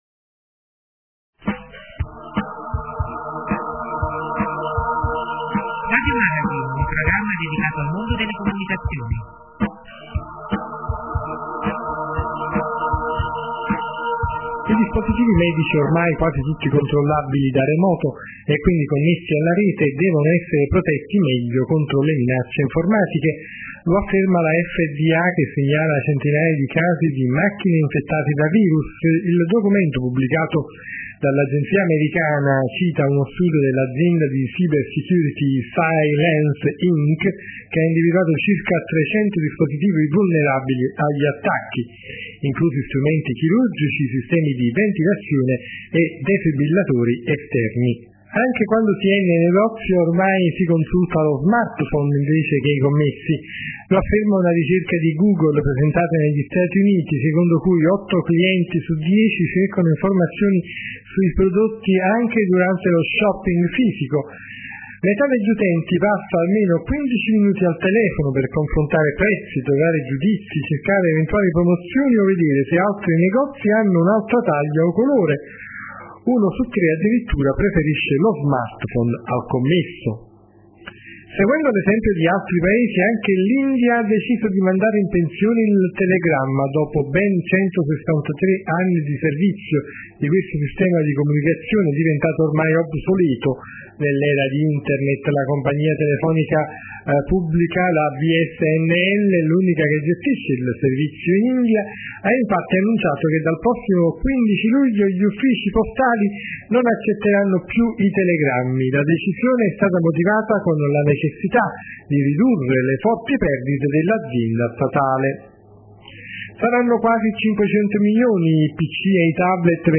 la nostra sintesi vocale